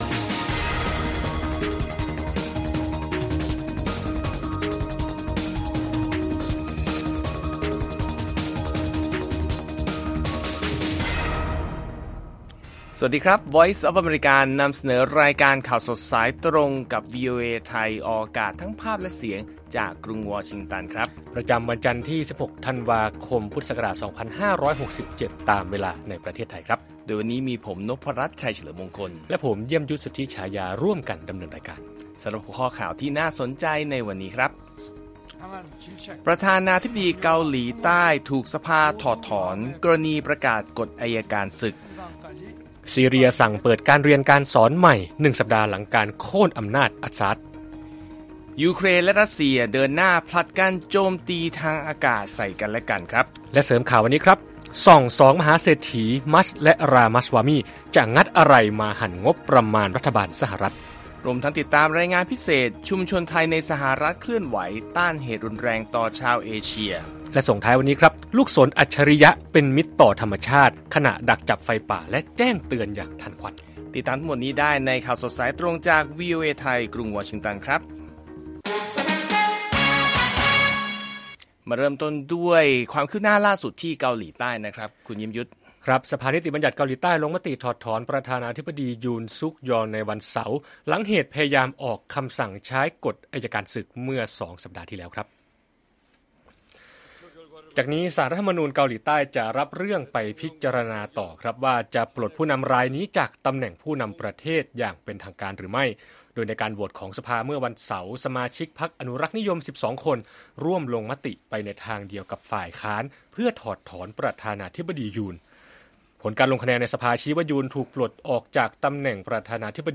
ข่าวสดสายตรงจากวีโอเอไทย วันจันทร์ที่ 16 ธันวาคม 2567